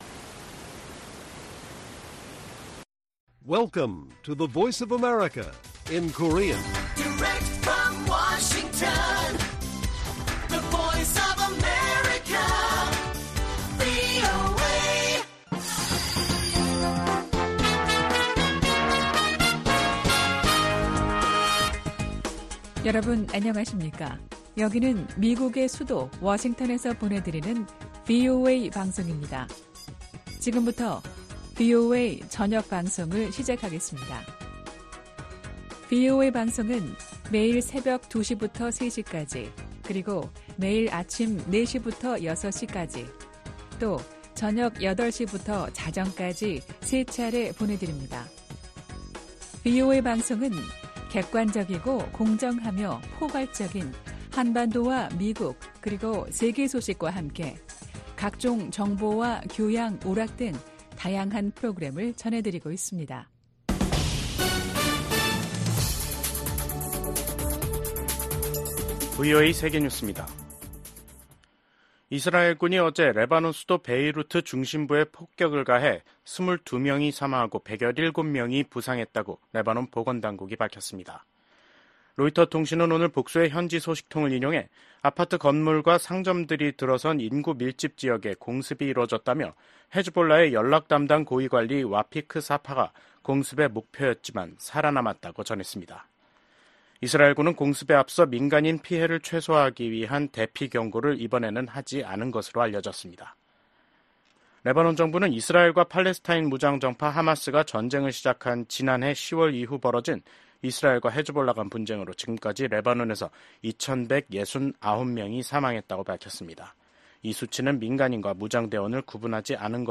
VOA 한국어 간판 뉴스 프로그램 '뉴스 투데이', 2024년 10월 11일 1부 방송입니다. 한국 작가로는 최초로 소설가 한강 씨가 노벨문학상 수상자로 선정됐습니다. 윤석열 한국 대통령은 동아시아 정상회의에서 북한과 러시아의 불법적 군사 협력을 정면으로 비판했습니다.